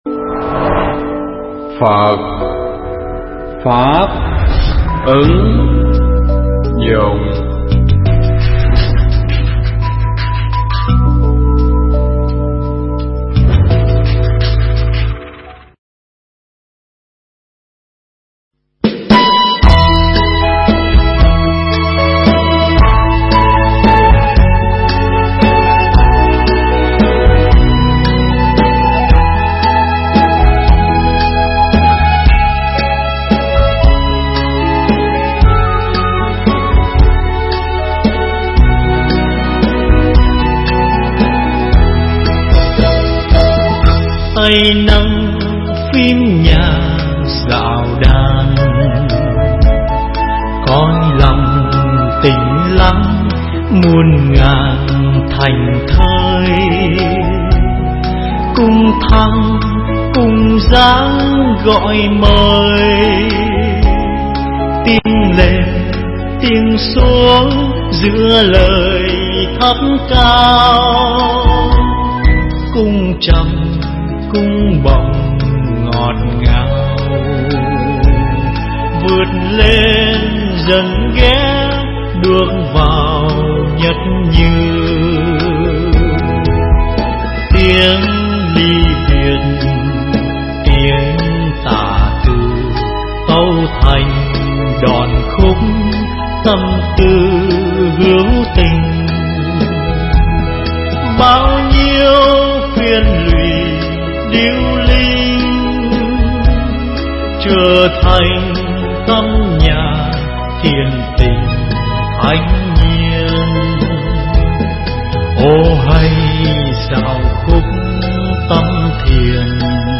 giảng tại chùa Quang Minh (Autralia)
Thuyết pháp